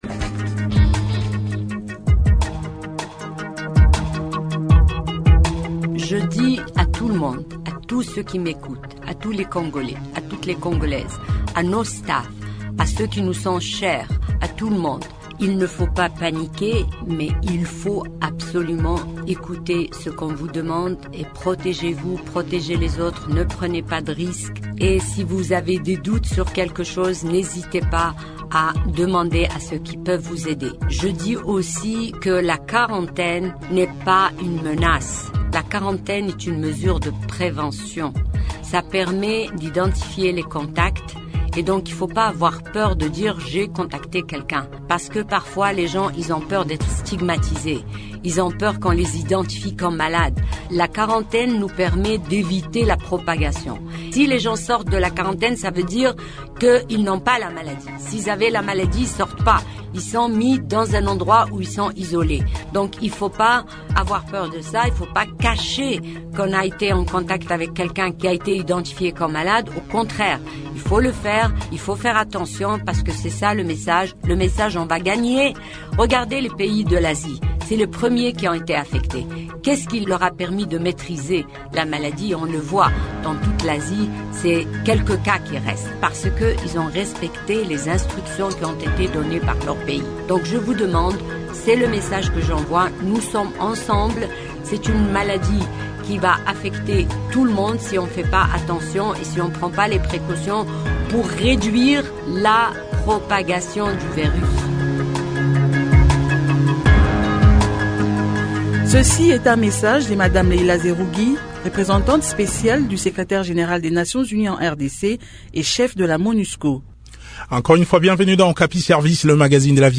analyste économique.